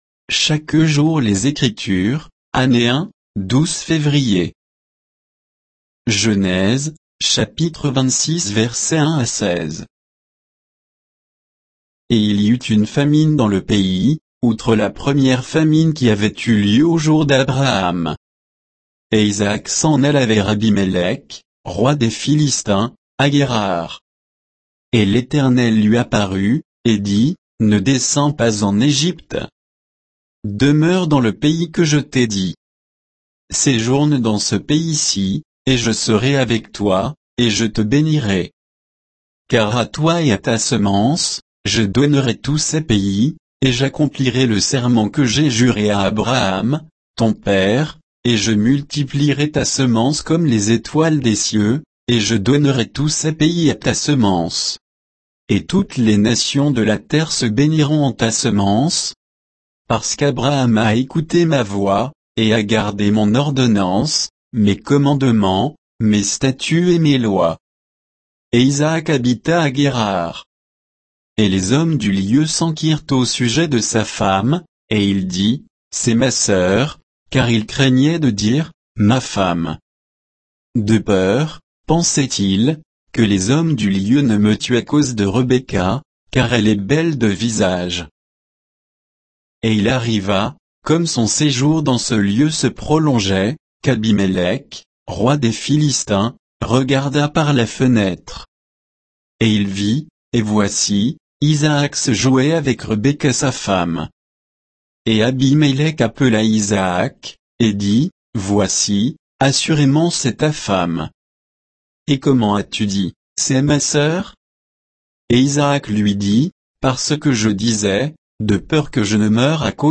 Méditation quoditienne de Chaque jour les Écritures sur Genèse 26